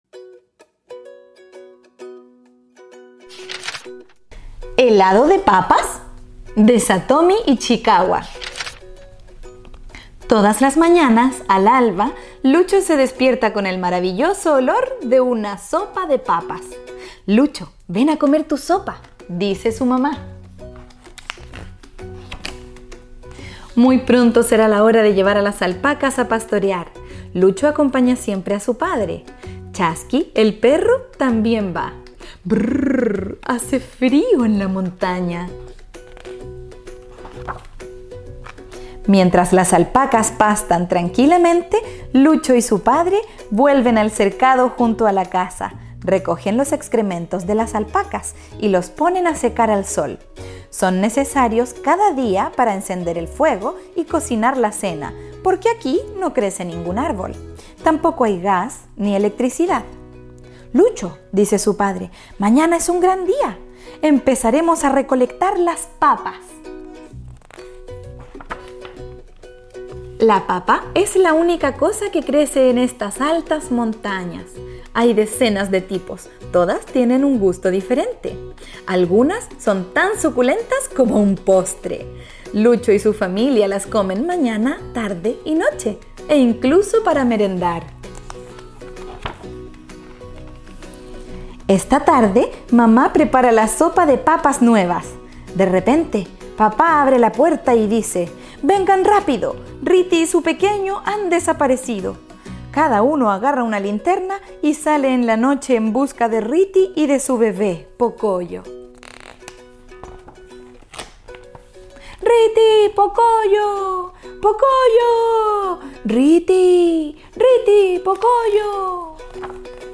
Audiolibro